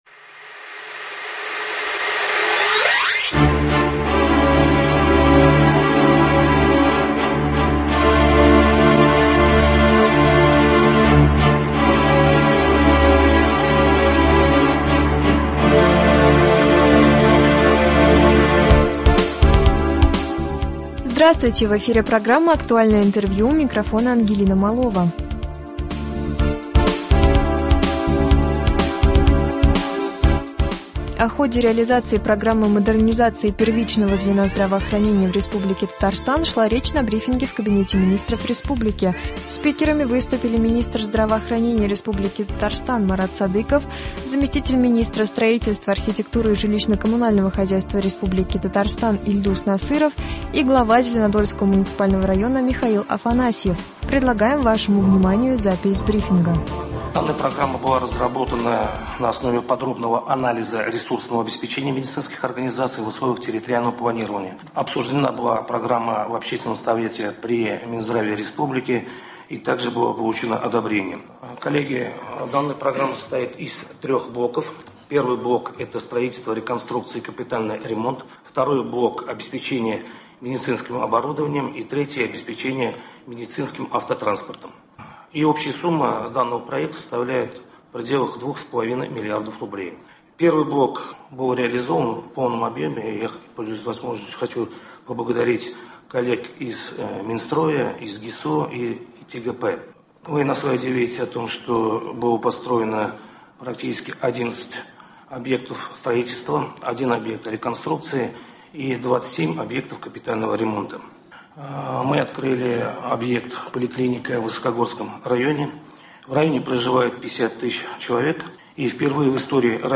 Актуальное интервью (25.05.22)
Об этом рассказал на брифинге в Кабинете министров Республики Татарстан министр здравоохранения РТ Марат Садыков.